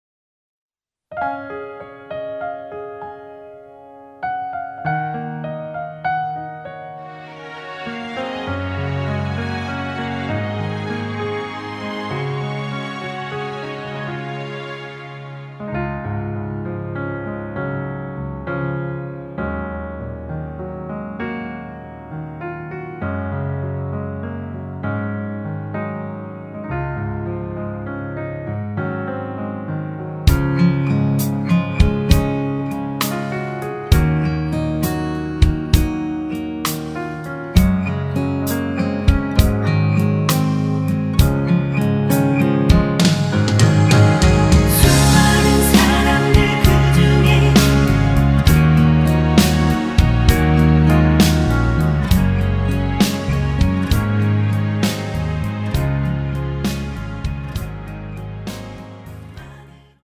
키 Db 가수